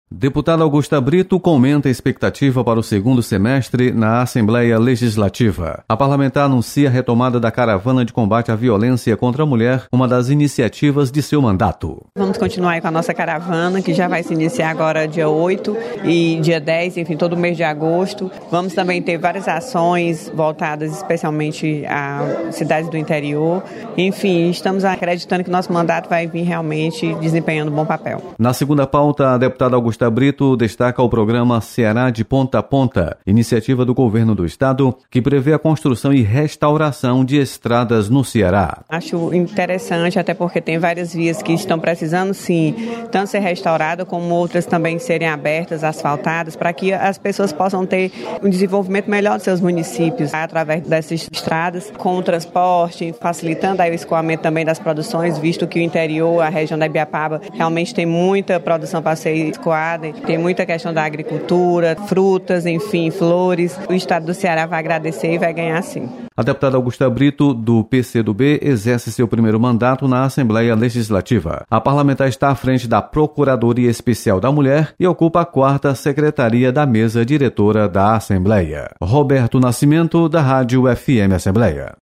Deputada Augusta Brito anuncia retomada da caravana de combate à violência contra a mulher.